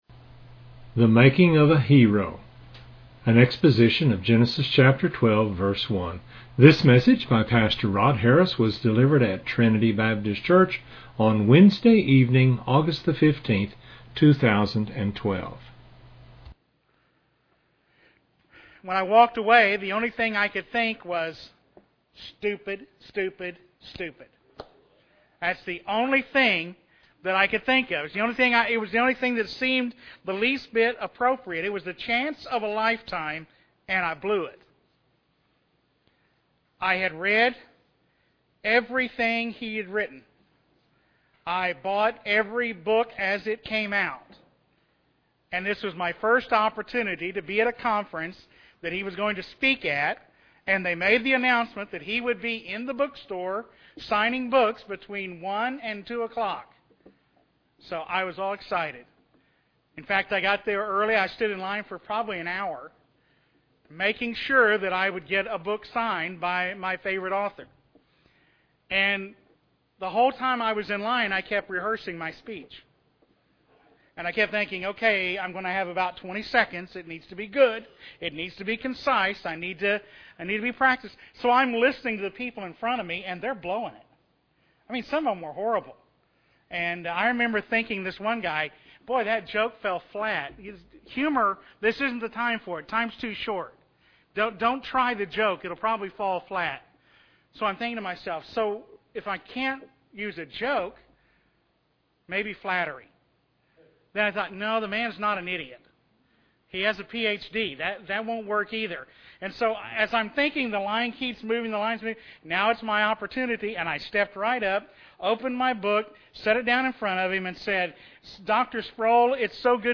This is an exposition of Genesis 12:1.